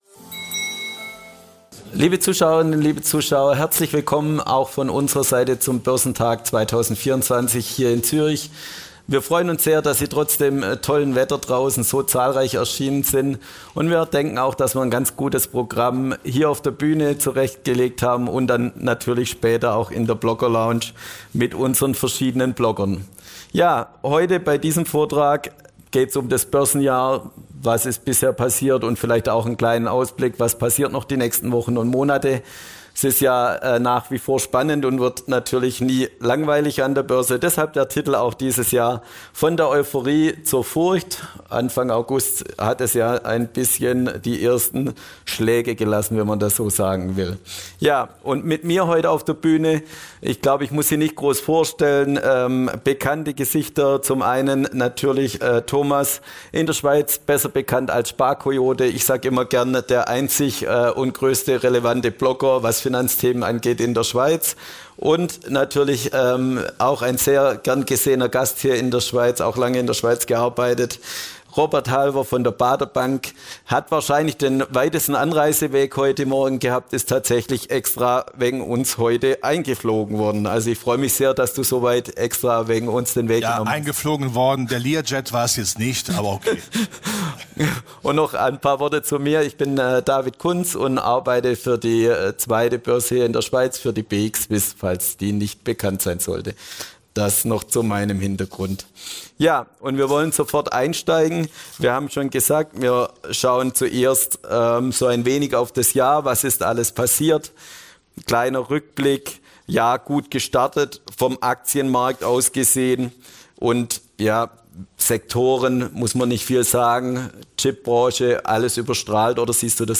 Ein besonderes Highlight auch auf dem diesjährigen Börsentag Zürich war die BX Swiss Blogger-Lounge. Bekannte YouTuber und Finanz-Influencer standen für Gespräche und Diskussionen bereit und vermittelten Ihr Wissen und Ihre Erfahrungen in Vorträgen.